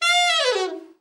ALT FALL   3.wav